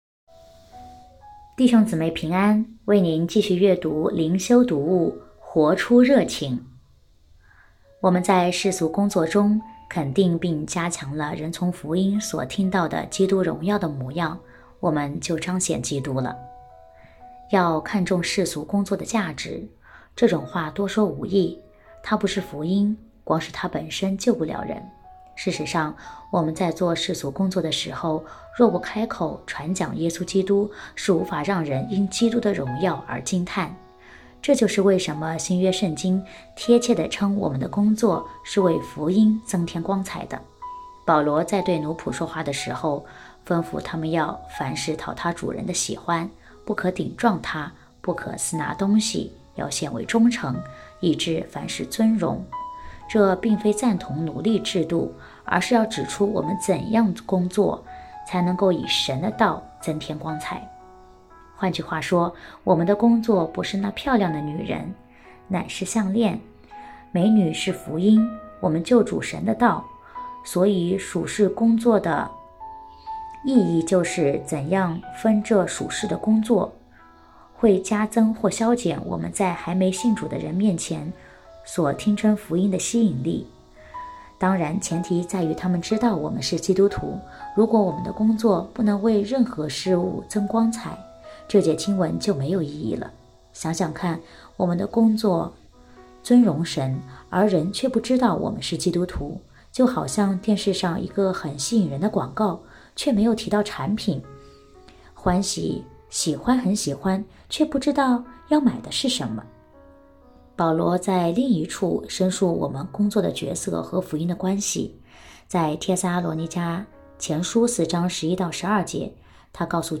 2024年7月18日 “伴你读书”，正在为您朗读：《活出热情》 欢迎点击下方音频聆听朗读内容 音频 https